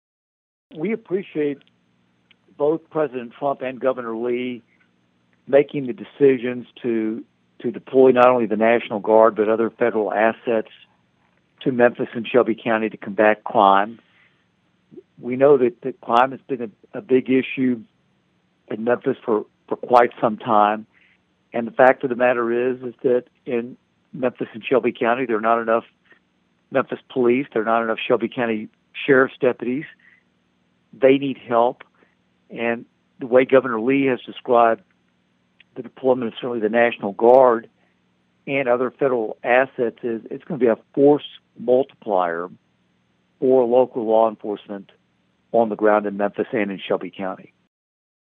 Congress Kustoff said the Memphis Safe Task Force will benefit the existing law enforcement agencies.(AUDIO)